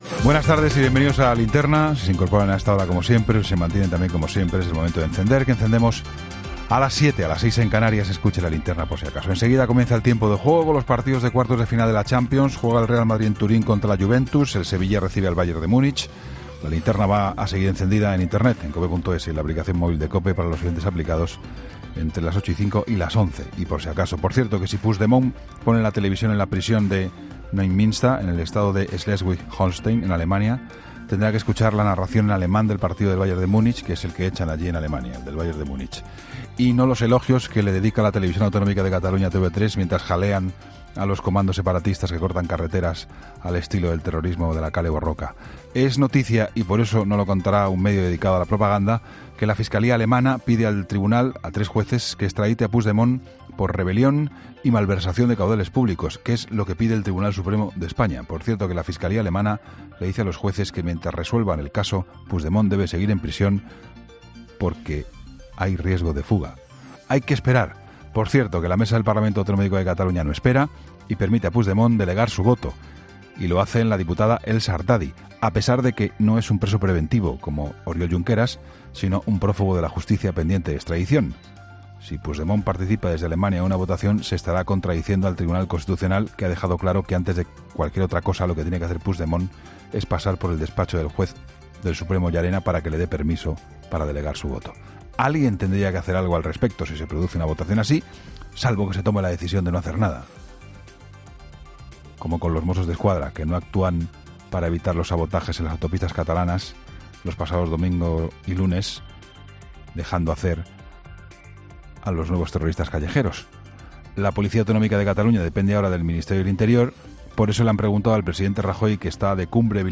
Juan Pablo Colmenarejo, director de ‘La Linterna’, ha centrado su análisis de este martes en la decisión de la Fiscalía alemana de respaldar la entrega de Carles Puigdemont tal y como solicita el juez del Tribunal Supremo Pablo Llarena